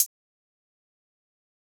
Hihat (Smooth).wav